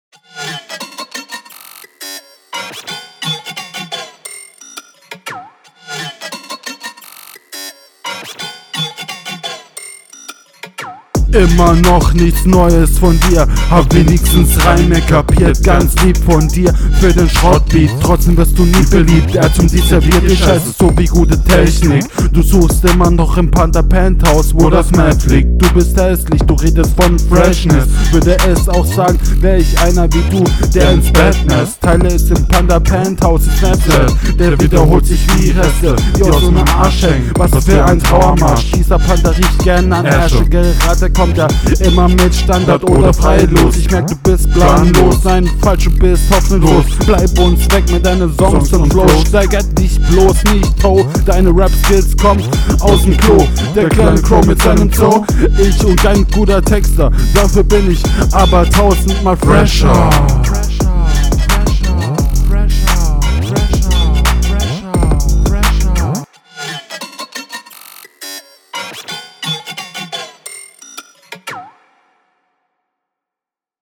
Sound immer noch gleich aber bisschen lauter(Der gleiche Tipp wie vorhin, lass dat jemanden abmischen …